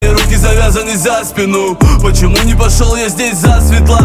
• Качество: 322, Stereo
мужской голос
русский рэп
Bass